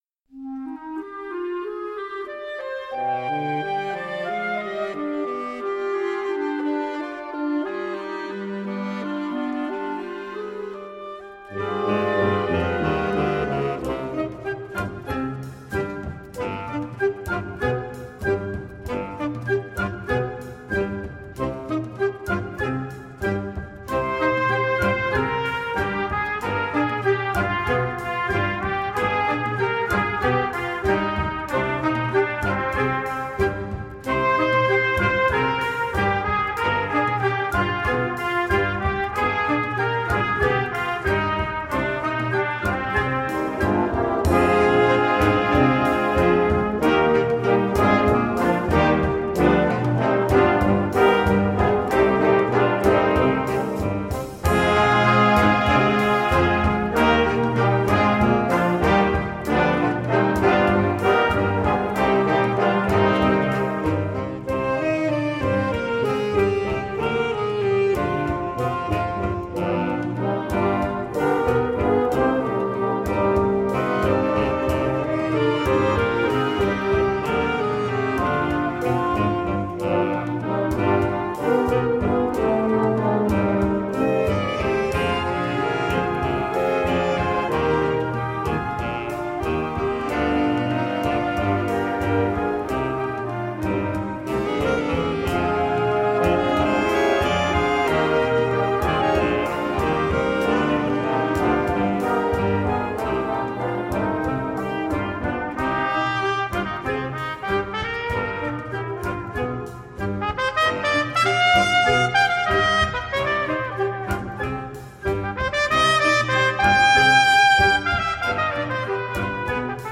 La fanfare